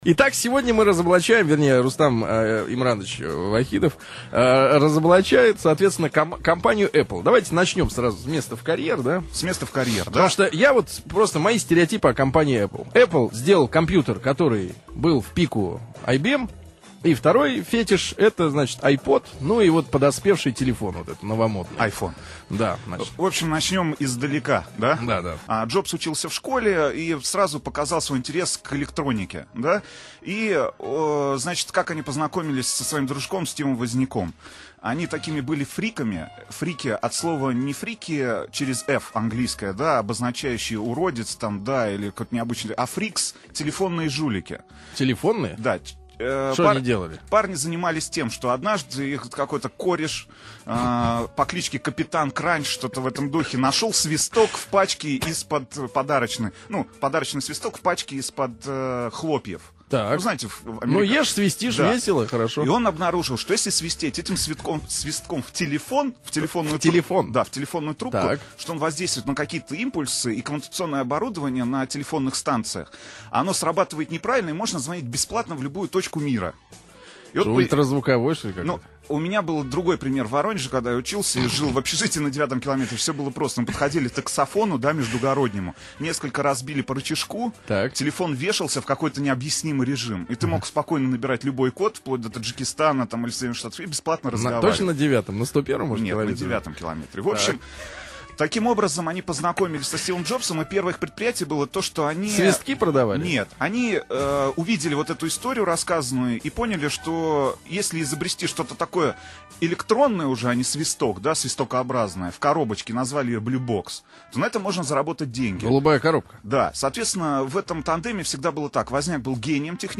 утреннее шоу